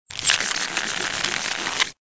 resources/phase_5/audio/sfx/SA_rolodex.ogg at master
SA_rolodex.ogg